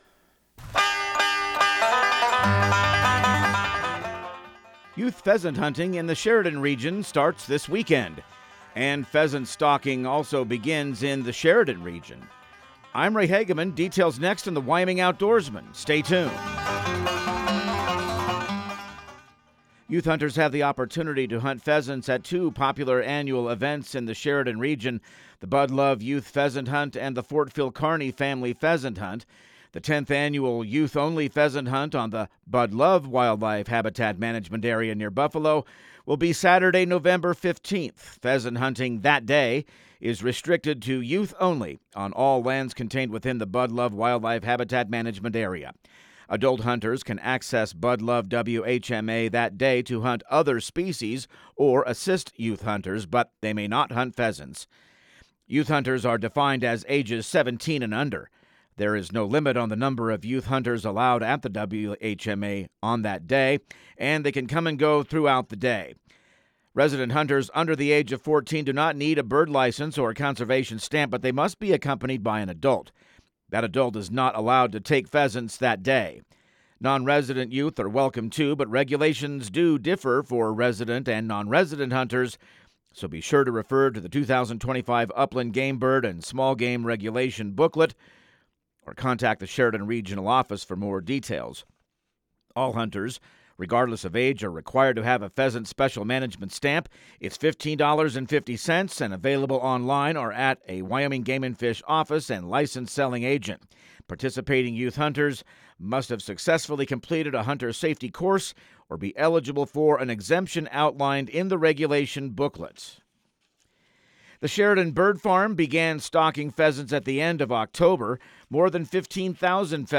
Radio news | Week of November 10